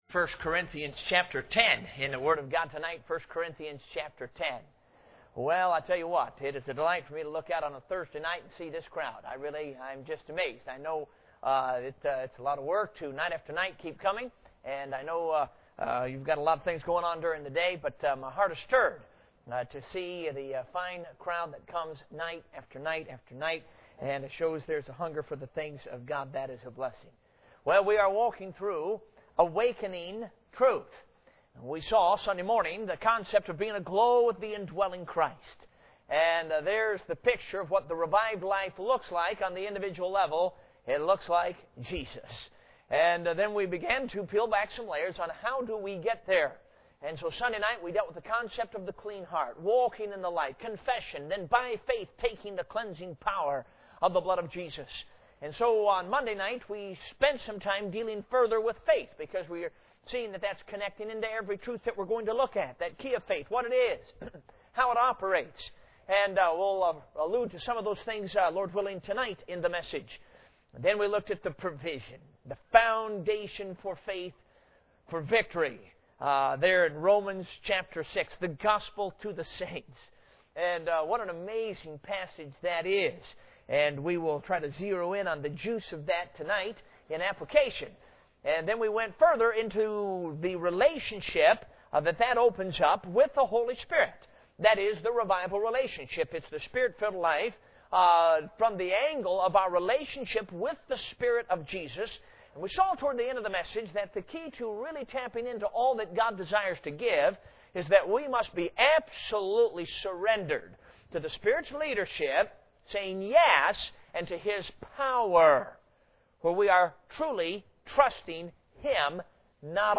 Passage: 1 Corinthians 10:13 Service Type: Revival Service